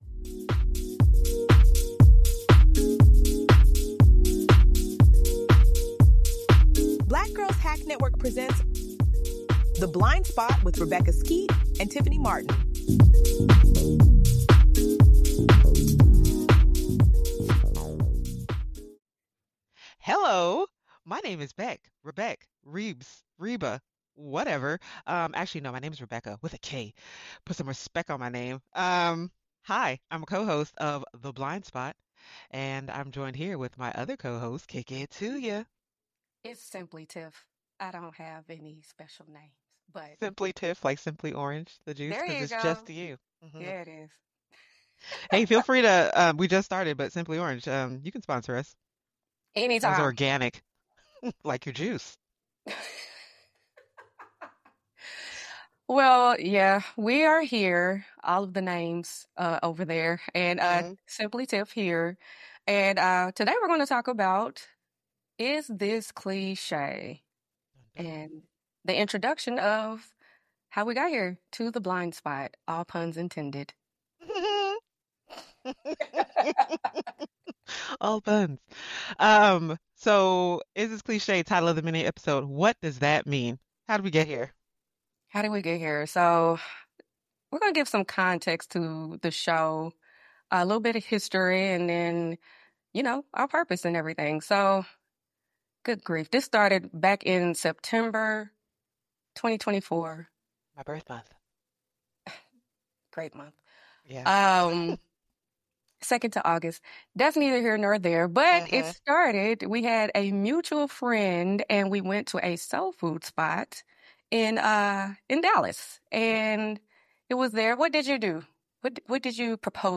From initial doubts to deep purpose, their candid conversation is full of laughs, real talk, and thoughtful reflection.